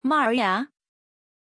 Aussprache von Maarja
pronunciation-maarja-zh.mp3